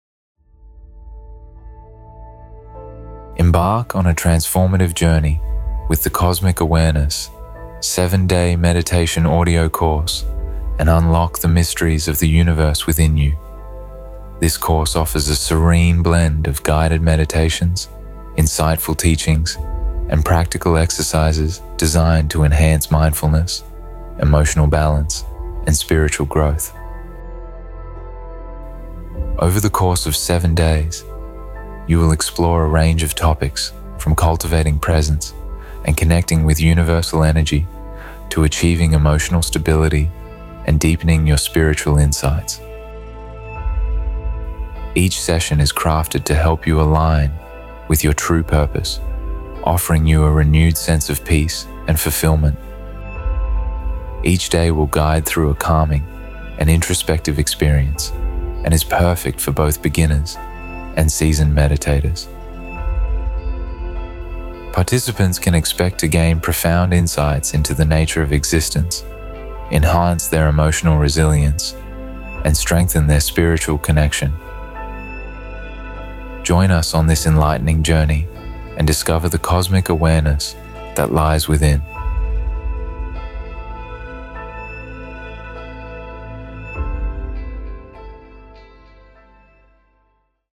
This course offers a serene blend of guided meditations, insightful teachings, and practical exercises designed to enhance mindfulness, emotional balance, and spiritual growth.
Each day will guide through a calming and introspective experience and is perfect for both beginners and seasoned meditators.